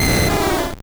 Cri de Draco dans Pokémon Or et Argent.